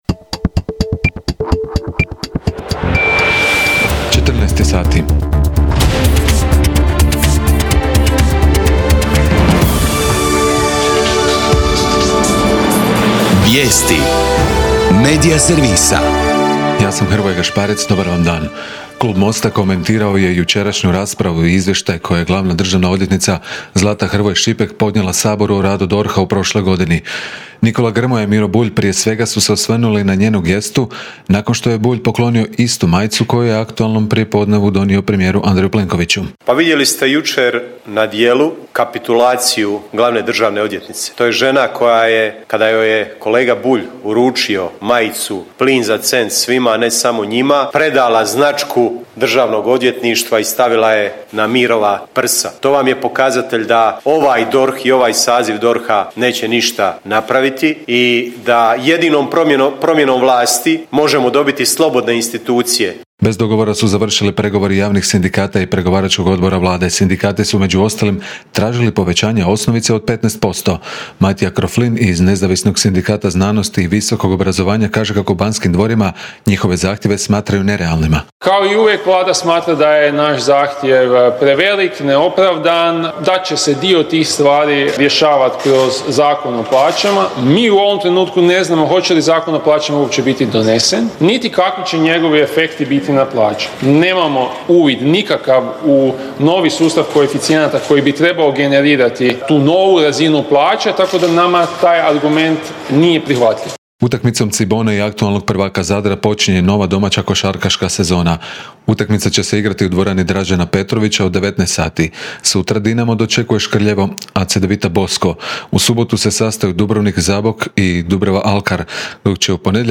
VIJESTI U 14